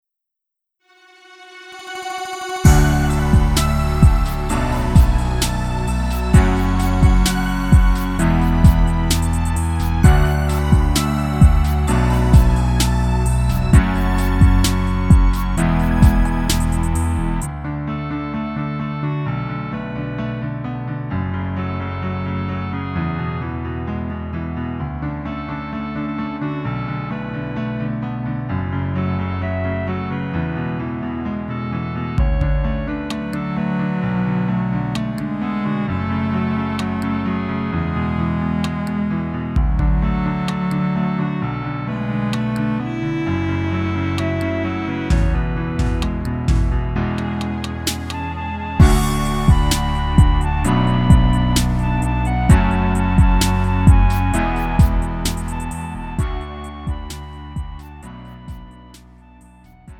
음정 -1키 3:43
장르 가요 구분 Lite MR